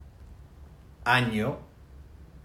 Además del estudio en sí, notamos que su español, en un sentido general, es bastante cuidadoso y estándar, sin muchos cambios dialectales.
La imagen de abajo presenta la onda sonora y el espectrograma de la palabra año, producido por nuestro participante y escuchado arriba.
Las vocales /a/ y /o/ presentan pulsos grandes, regulares y periódicos, lo que refleja el flujo de aire constante y abierto y la fuerte sonorización típicos de la producción de vocales.
El espectrograma refuerza este contraste al mostrar bandas fuertes para las vocales y un murmullo nasal concentrado de baja frecuencia para /ɲ/ (Russell, 2005).